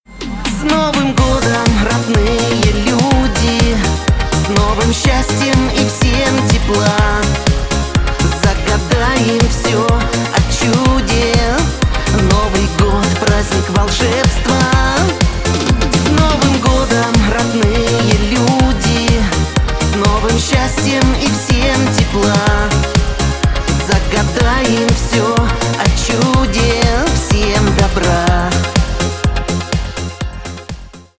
новогодние , шансон